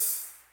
Open Hat (Touch The Sky).wav